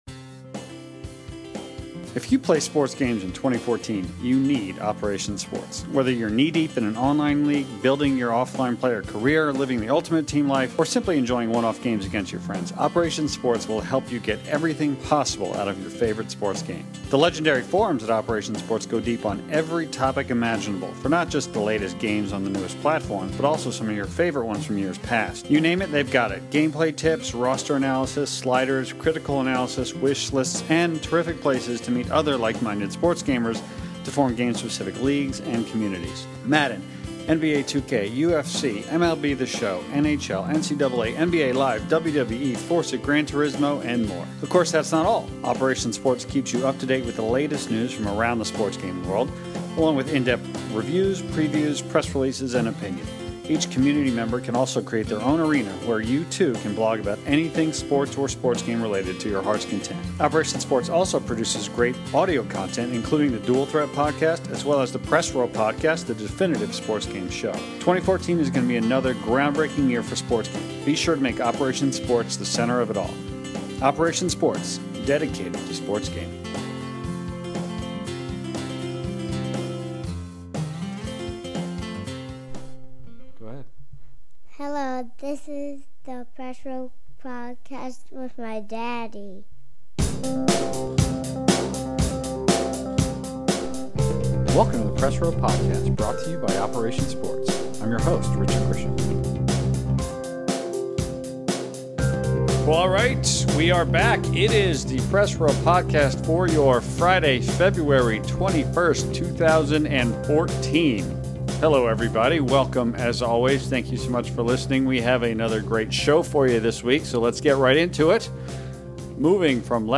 In the 73rd episode of the Press Row Podcast, our regular panel dives into a bunch of interesting topics surrounding what’s quickly become a busy spring sports game season.